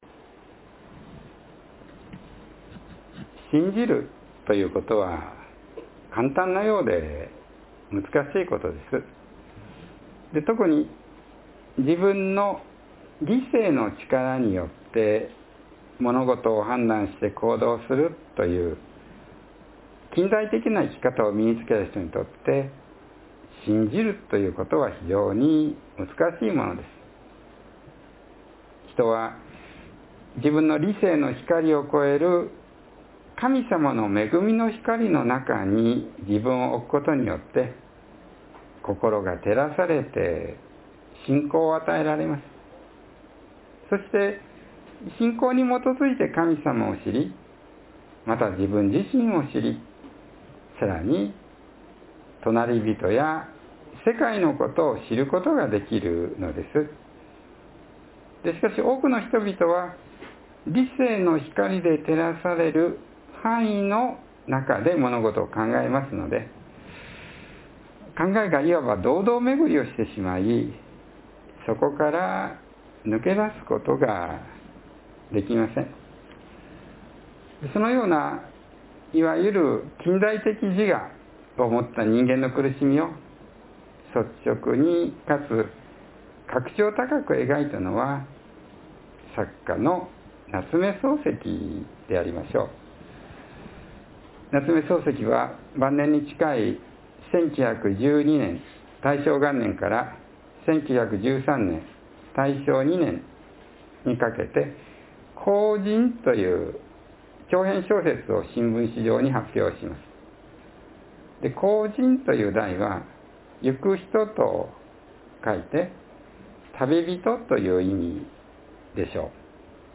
（12月8日の説教より） Believing is something that seems simple, but is actually quite difficult.